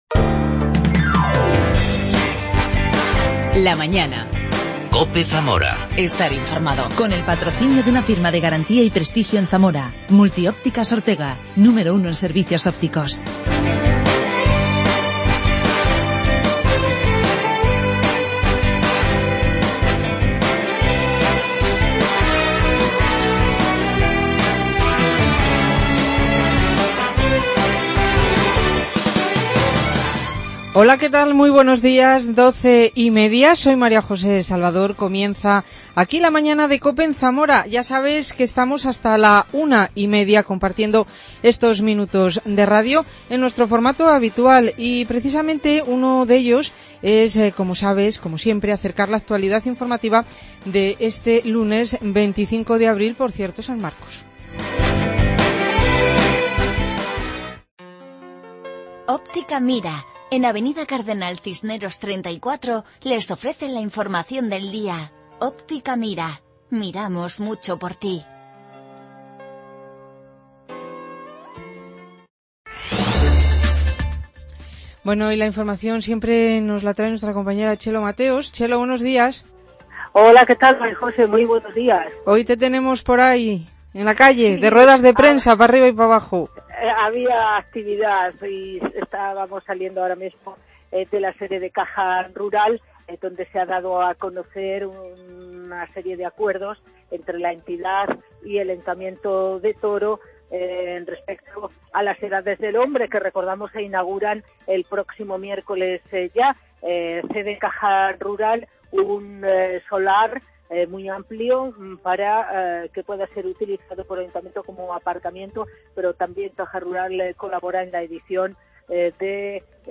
AUDIO: El concejal de seguridad ciudadana, y Primer Teniente de Alcalde, Antidio Fagúndez habla del plan de movilidad urbana sostenible.